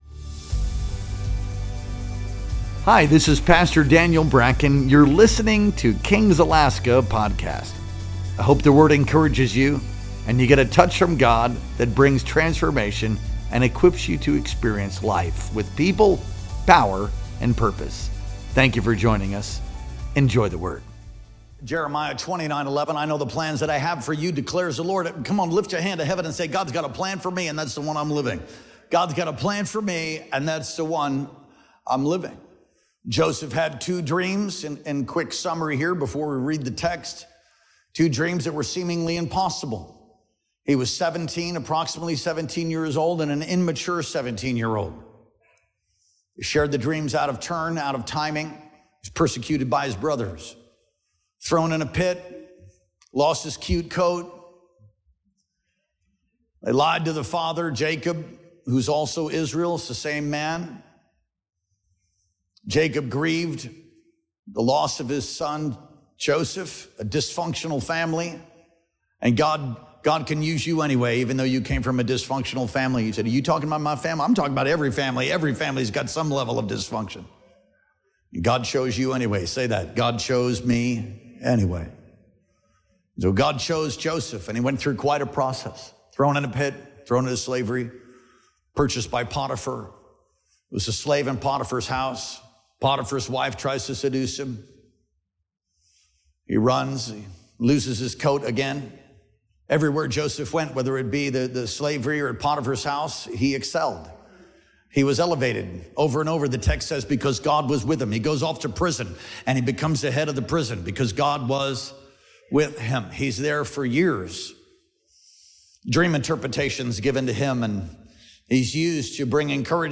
Our Sunday Worship Experience streamed live on July 13th, 2025.